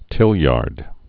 (tĭltyärd)